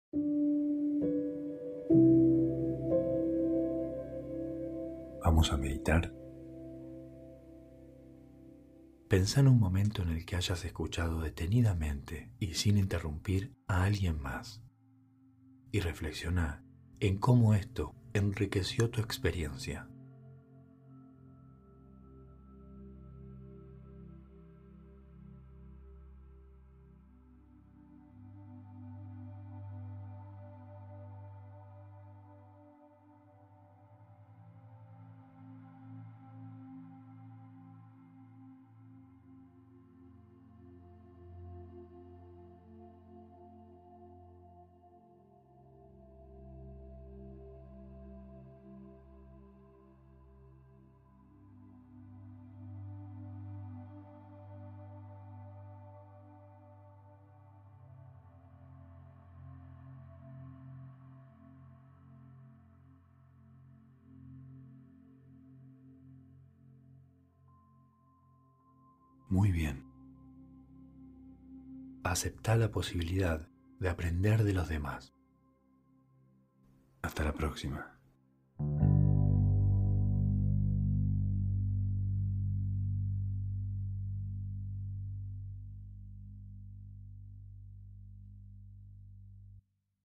Meditación de 1 minuto para reflexionar.